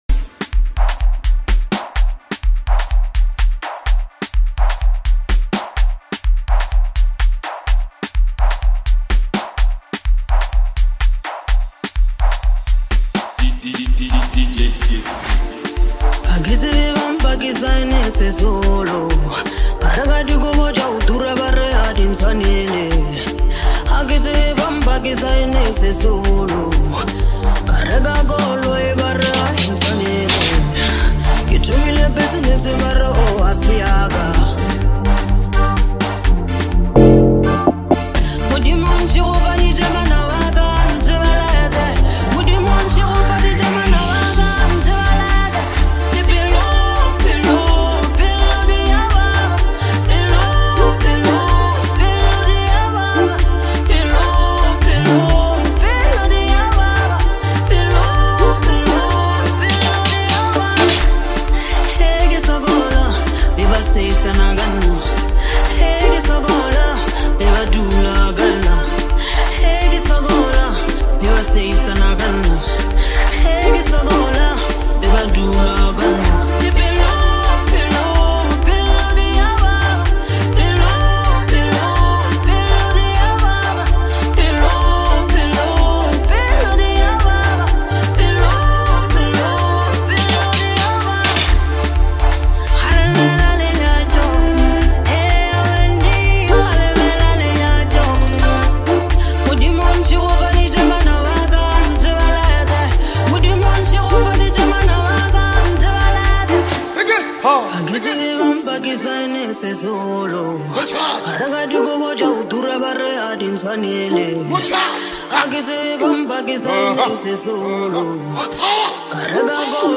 The instrumental arrangement is polished and well-balanced